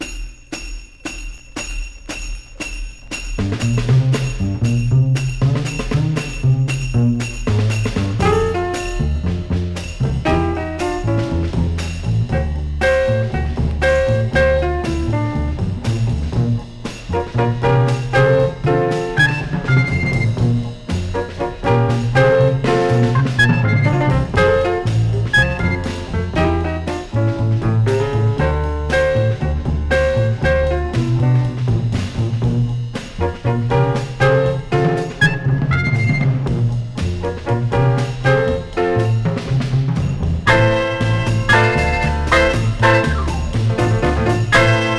グルーヴィーでヒップな演奏、小編成で色彩豊かなアレンジ、遊び心満載のエキサイティングな好盤。
Jazz, Soul-Jazz, Easy Listening　USA　12inchレコード　33rpm　Stereo